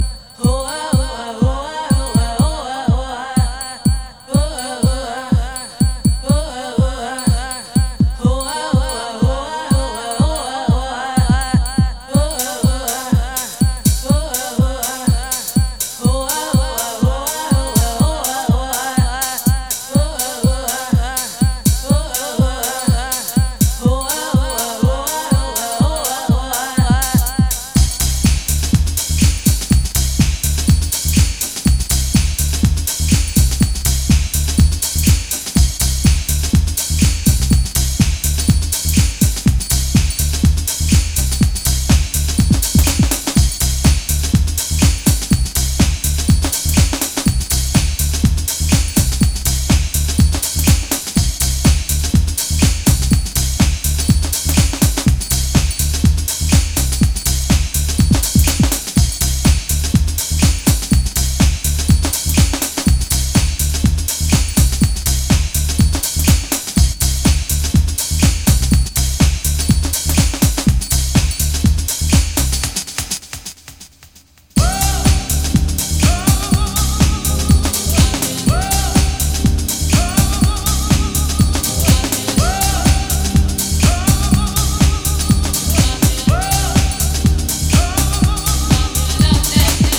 ジャンル(スタイル) HOUSE CLASSIC / DEEP HOUSE